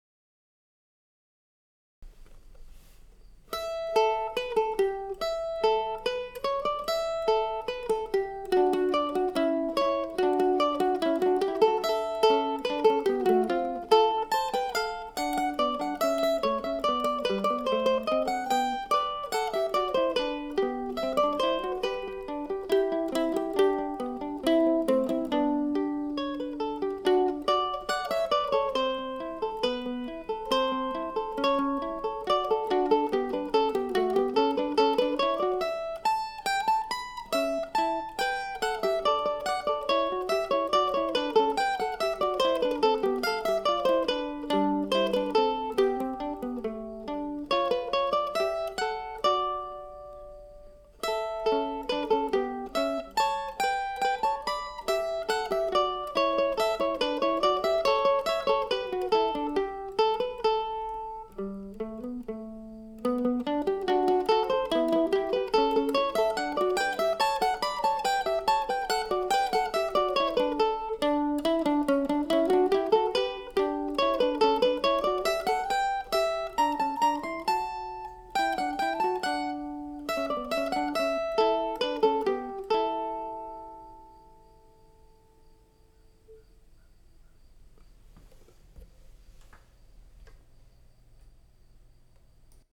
I have many good memories of visits (concerts, hiking, camping) there and so I used this title back in 2000 for this duet.
Today's track was recorded using the free sample version of something called Mixcraft 6 and I was very pleased with how easy it was to use.